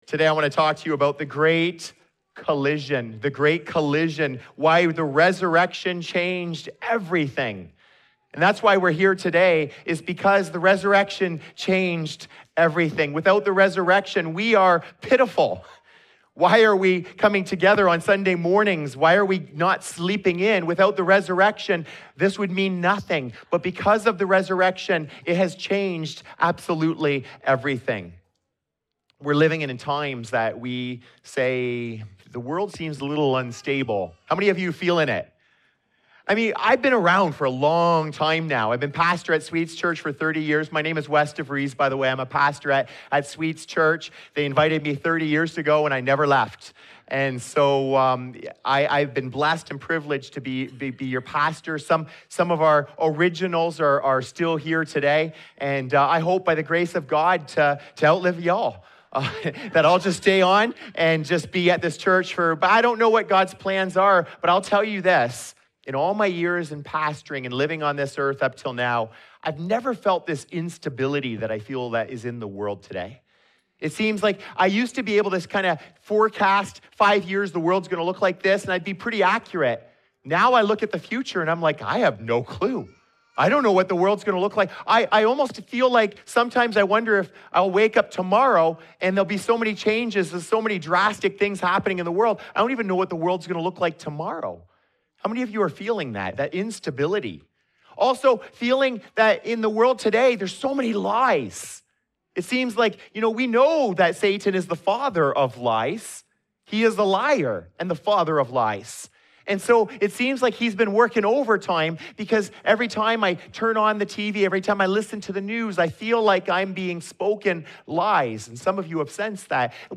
In a world that feels unstable and uncertain, this Easter message points to the one unshakable truth—the resurrection of Jesus Christ. Looking at Psalm 16 and Acts 2, this sermon shows how Jesus faced death with hope, broke the power of the grave, and rose into eternal joy.